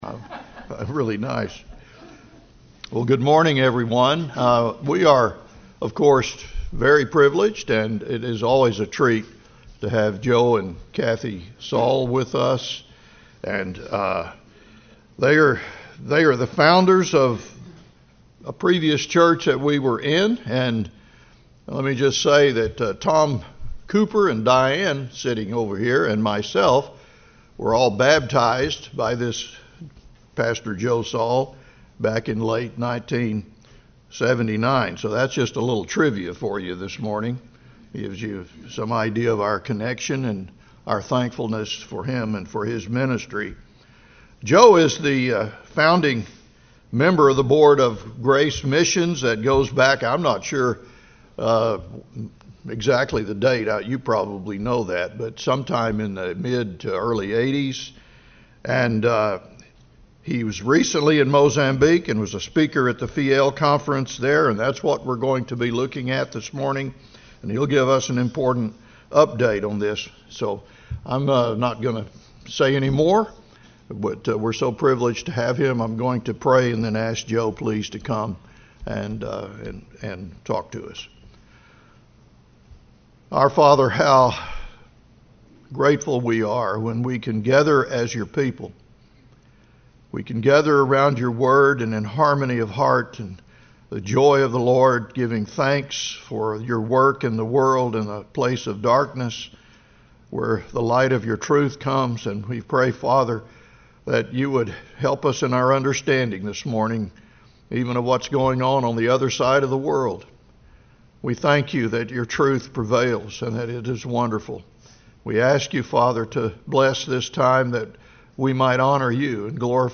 2025 Topic: Missions Service Type: Sunday School